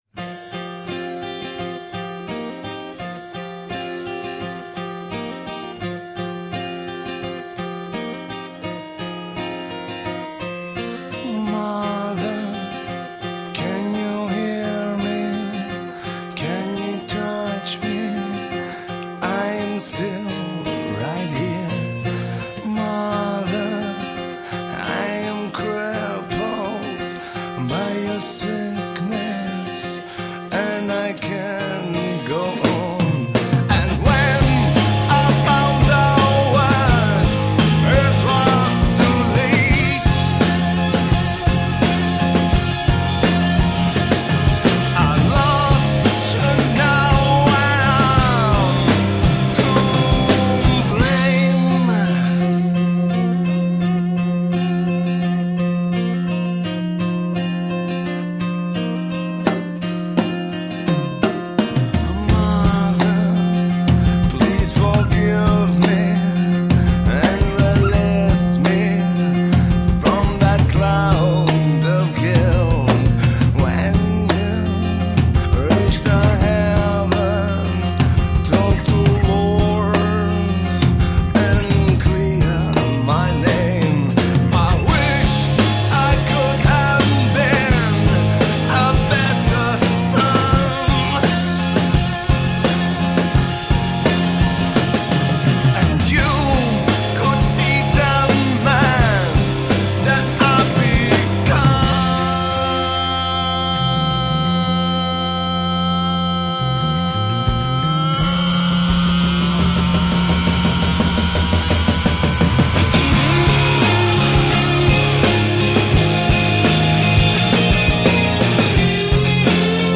Rock, alternative rock, indie rock, psych rock.
Tagged as: Alt Rock, Rock, Hard Rock, Prog Rock